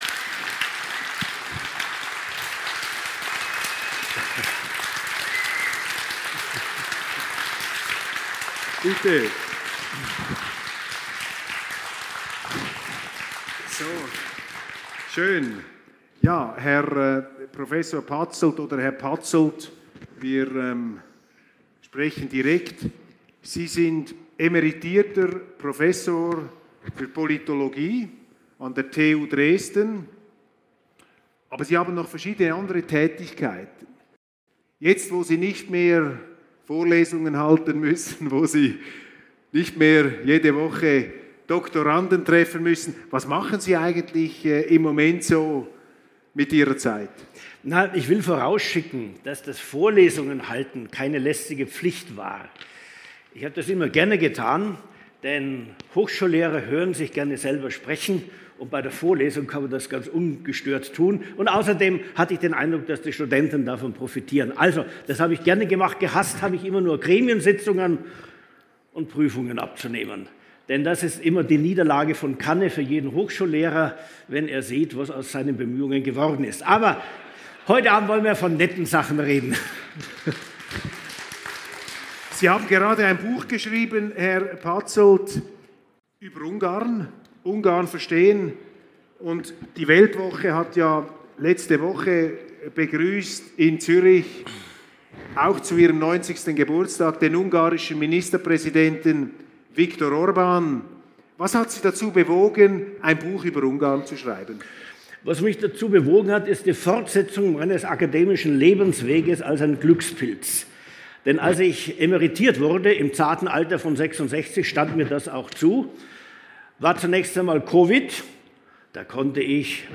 // Gespräch in Dresden - Patzelt & Köppel
Die WELTWOCHE in Dresden
Deutschlands grosse Chance: Weltwoche-Chef Roger Köppel und Politologe Werner J. Patzelt über Illusionen, Brandmauern und die Freiheit aus dem Osten Audiofile , leider mit einigen „Hackeln“